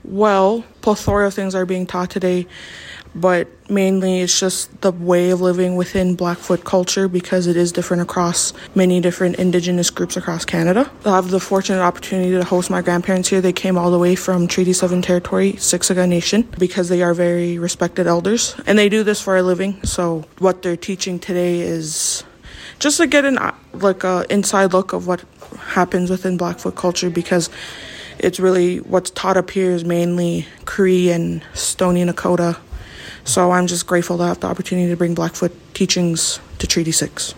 macewan-blackfoot-teachings-audio-clip-01.mp3